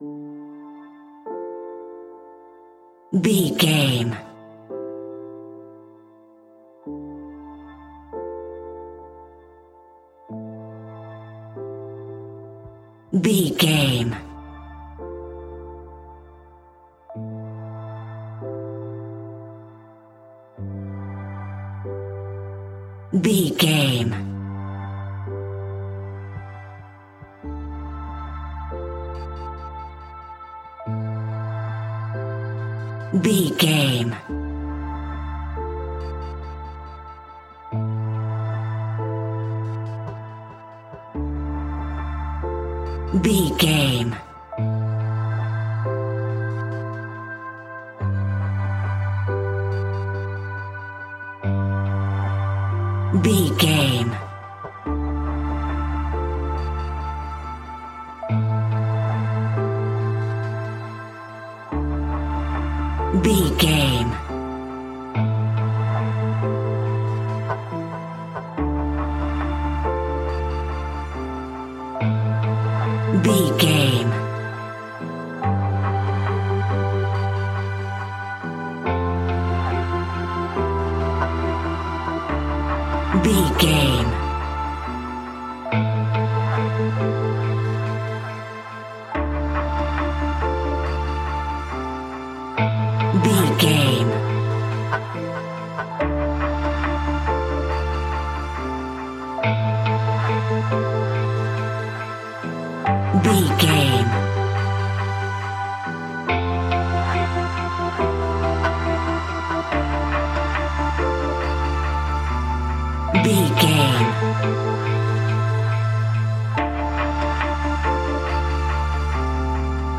Aeolian/Minor
Slow
ambient
dreamy
ethereal
melancholic
synthesiser
piano
strings
drum machine